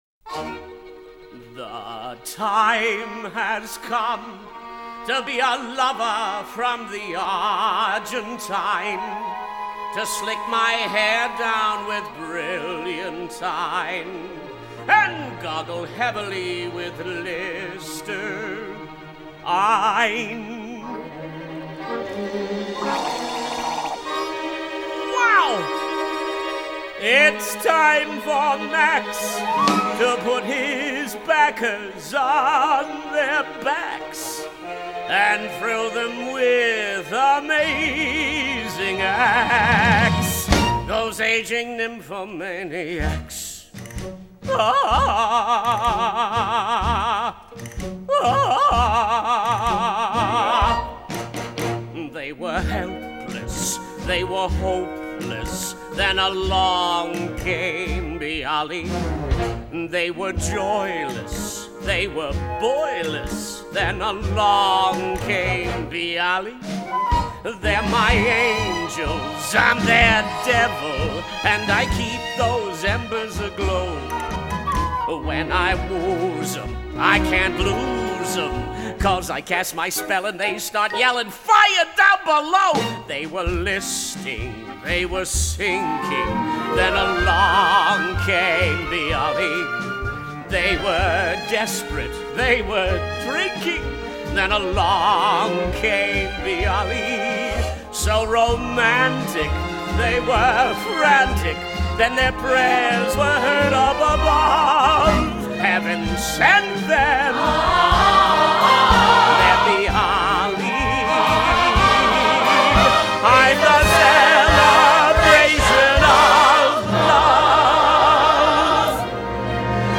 2001   Genre: Musical   Artist